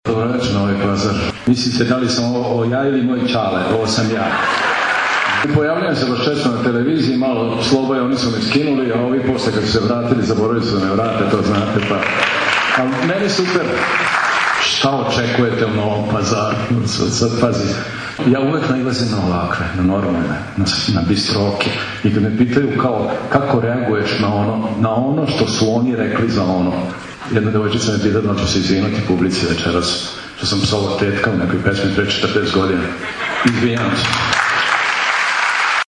Atmosfera sa koncerta